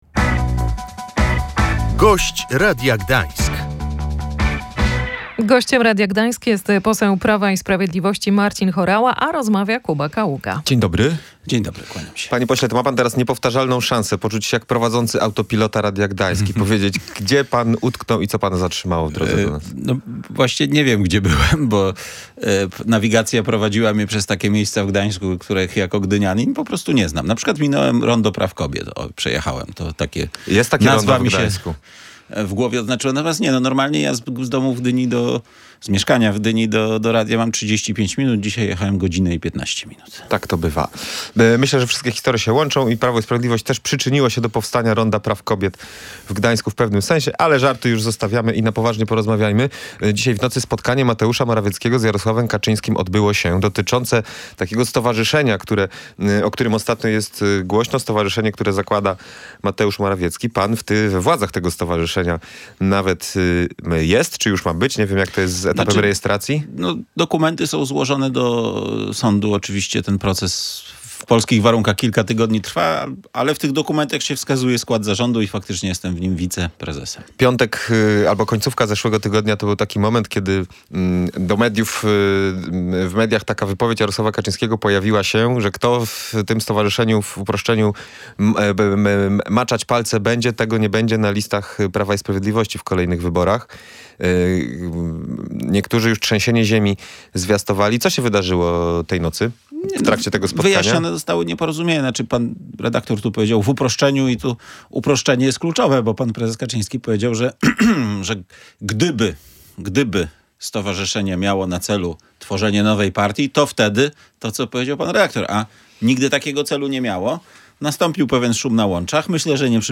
Tej nocy wyjaśnione zostało nieporozumienie – tak o nocnym spotkaniu prezesa PiS Jarosława Kaczyńskiego z byłym premierem Mateuszem Morawieckim mówił na antenie Radia Gdańsk Marcin Horała.